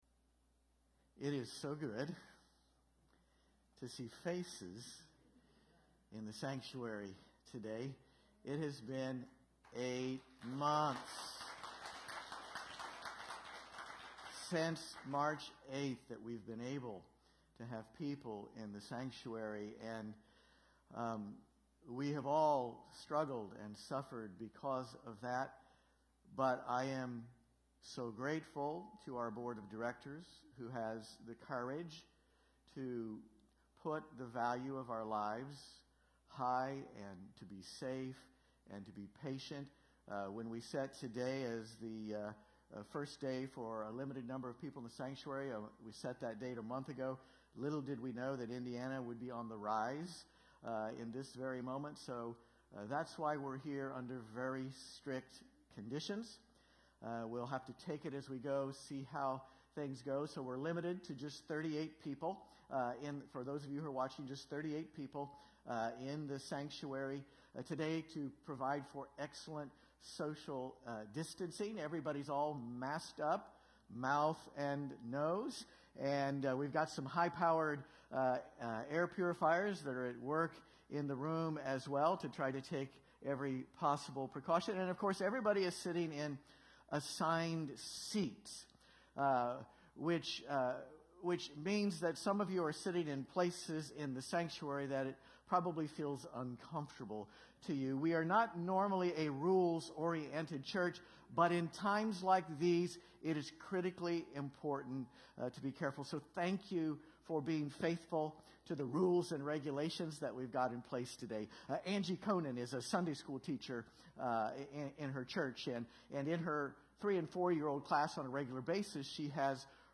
This Sunday we’ll continue our sermon series about some of the great saints throughout church history by focusing on one of the most colorful and unlikely of all the saints.